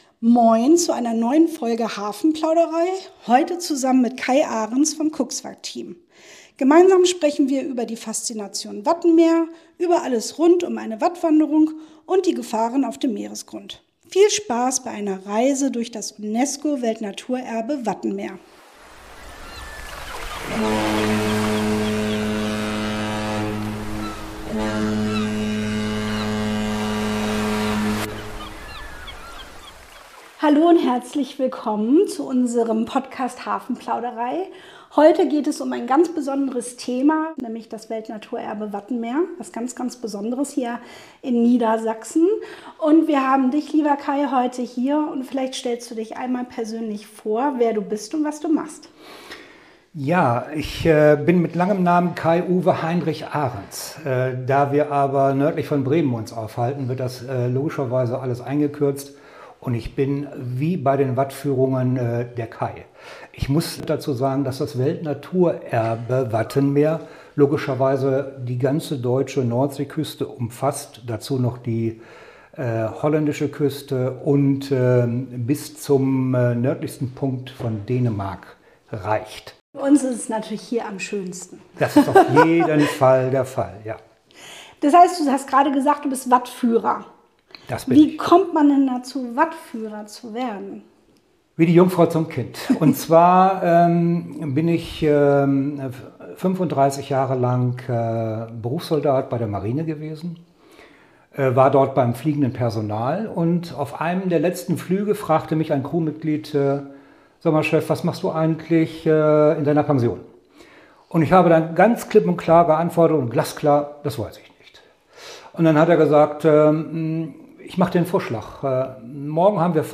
Gemeinsam mit einem erfahrenen Wattführer spricht sie über die Faszination des Wattwanderns, überraschende Begegnungen im Schlick und warum das UNESCO-Weltnaturerbe mehr ist als nur Matsch unter d...